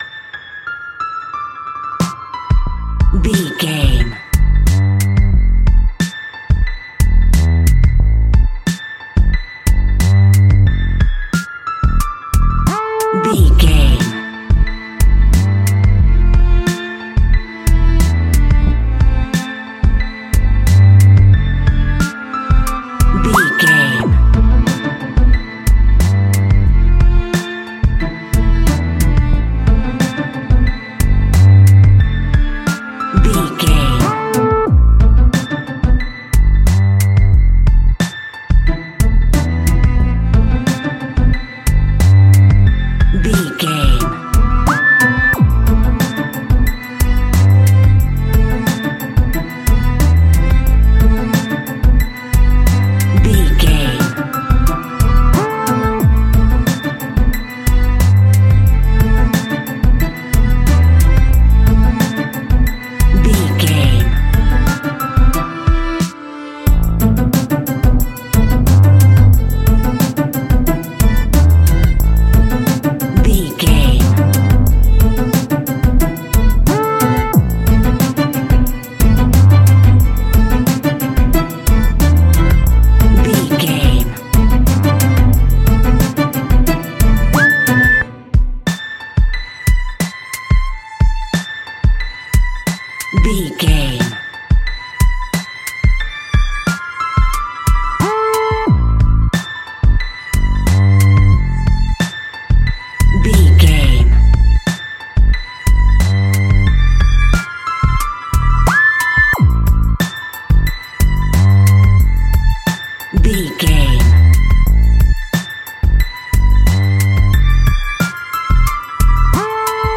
Hip hop music meeets classical.
Aeolian/Minor
chilled
laid back
groove
hip hop drums
hip hop synths
piano
hip hop pads